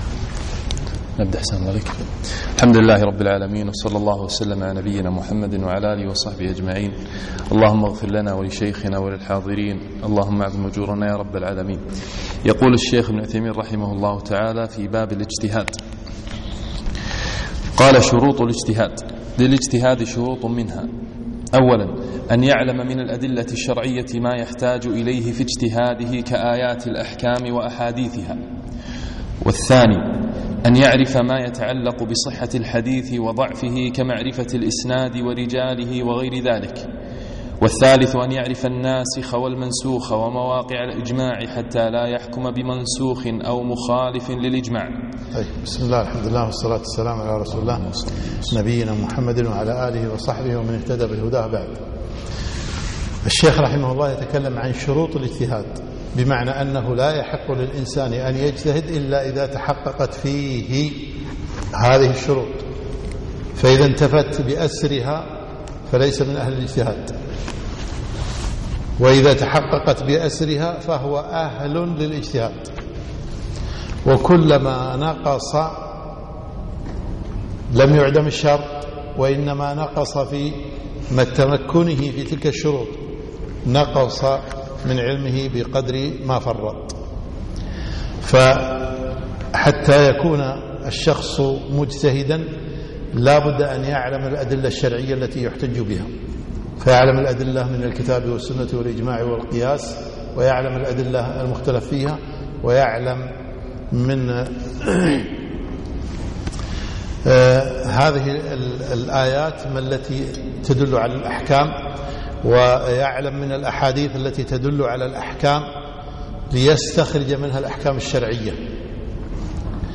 الدرس الثامن